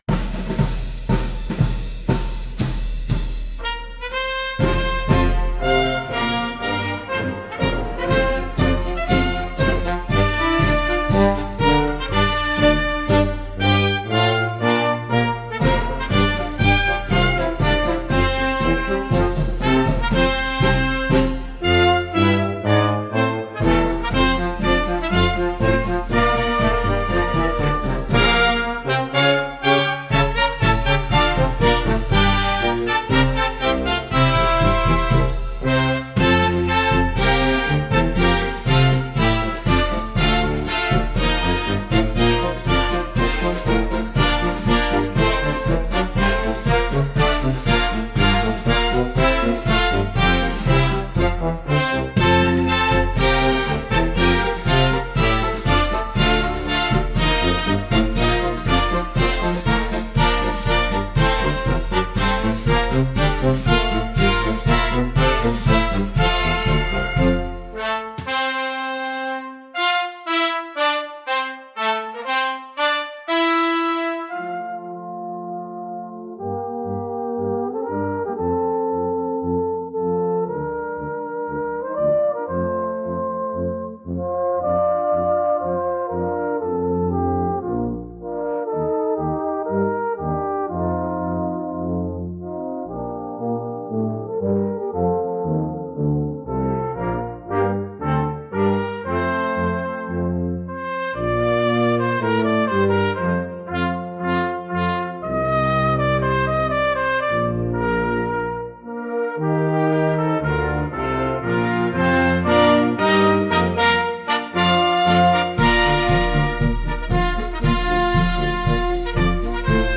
06-hymn-ii-lo-orkiestra-deta-instrumentalnie.mp3